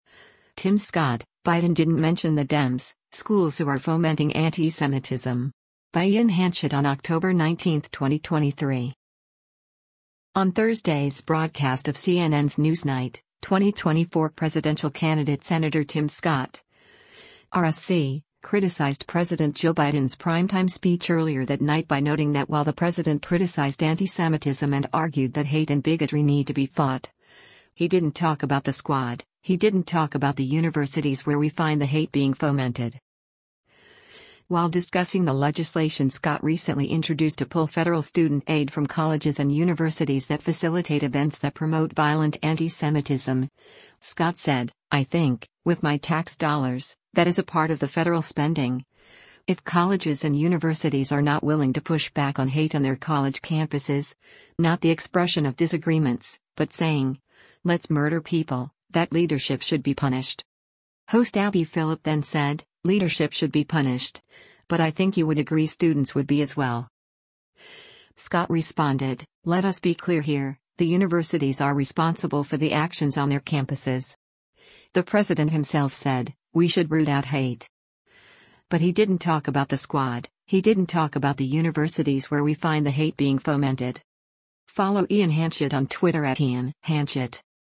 On Thursday’s broadcast of CNN’s “Newsnight,” 2024 presidential candidate Sen. Tim Scott (R-SC) criticized President Joe Biden’s primetime speech earlier that night by noting that while the President criticized antisemitism and argued that hate and bigotry need to be fought, “he didn’t talk about the Squad, he didn’t talk about the universities where we find the hate being fomented.”
Host Abby Phillip then said, “Leadership should be punished, but I think you would agree students would be as well.”